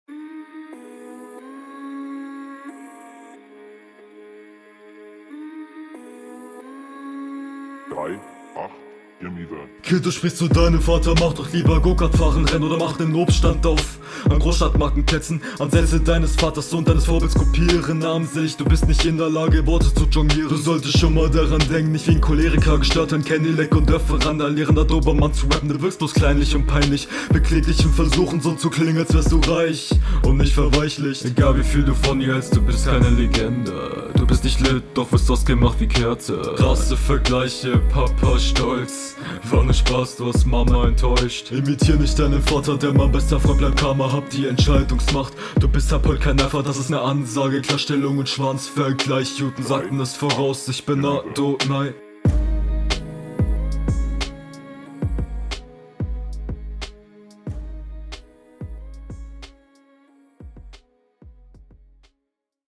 Flow: auch hier gute Ansätze an manchen Stellen aber nicht ganz flüssig Text: gegnerbezug am …
Der Flow und die Mische sind solide, alles ist onbeat, die Mische klingt jetzt nicht …